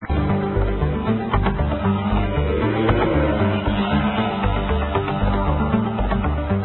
3 campionamenti di canti di nativi americani